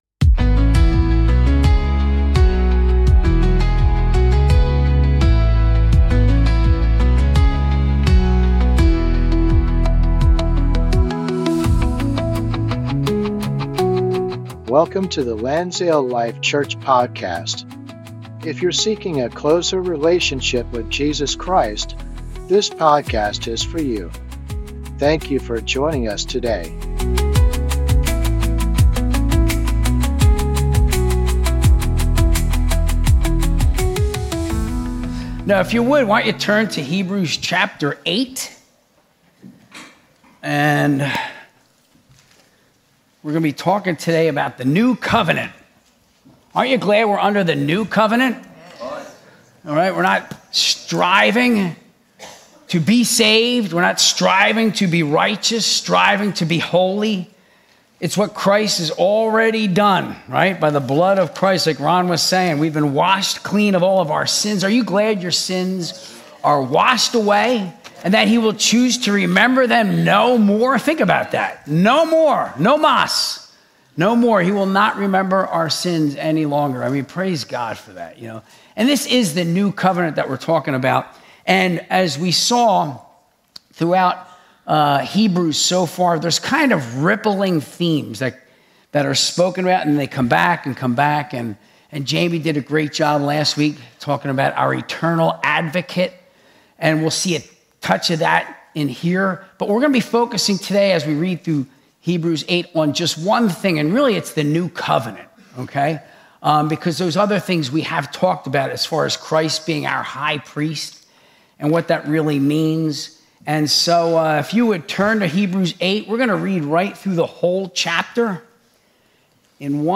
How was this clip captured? Sunday Service - 2025-09-28